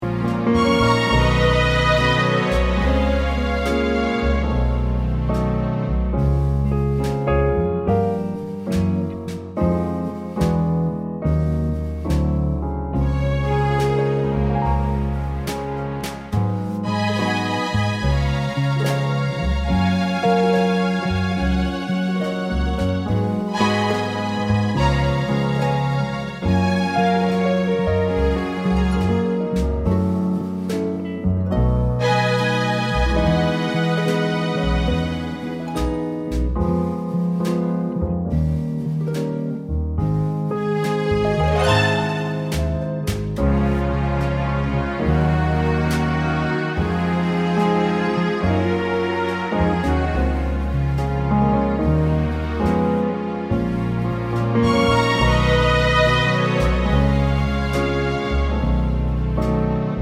Cut Down Glam Rock 3:25 Buy £1.50